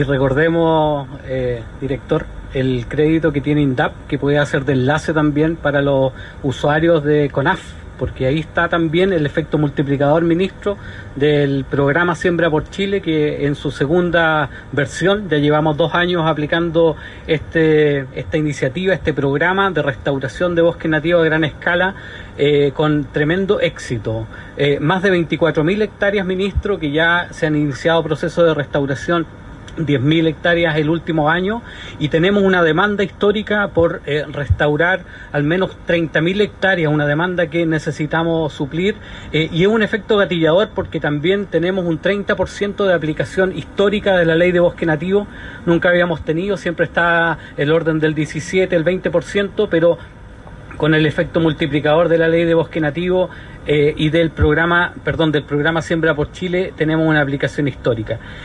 Audio Director ejecutivo de CONAF, Christian Little.